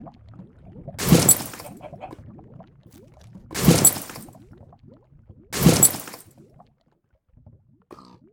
The horse armor equipping sound is unusually loud
The sound is unusually loud.